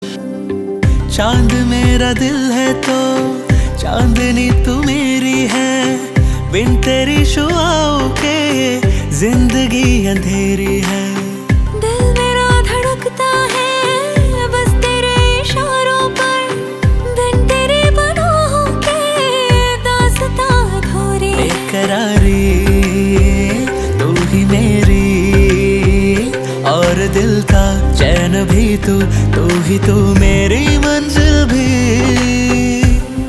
Melodious Fusion